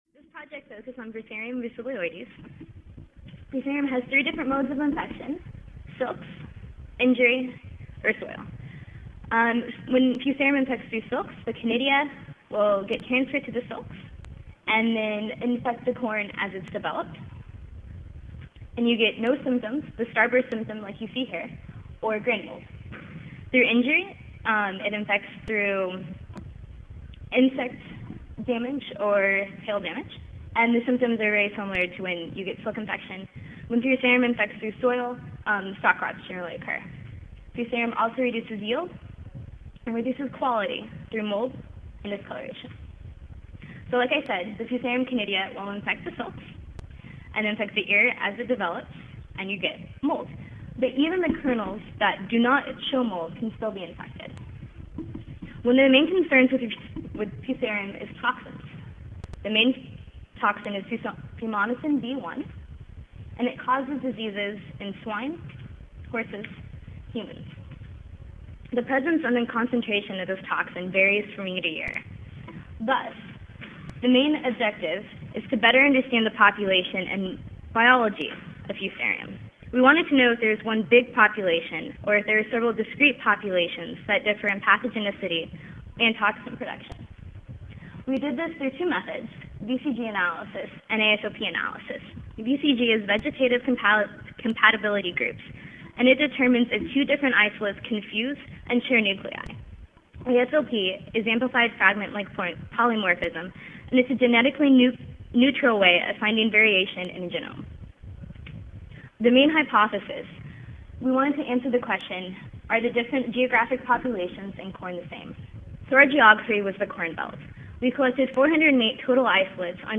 Oral Session
Long Beach Convention Center, Room 101A, First Floor
Audio File Recorded presentation